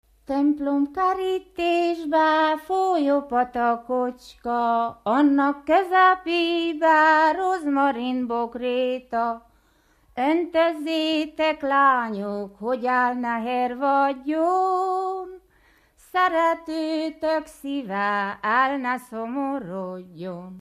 Moldva és Bukovina - Bukovina - Hadikfalva
Stílus: 4. Sirató stílusú dallamok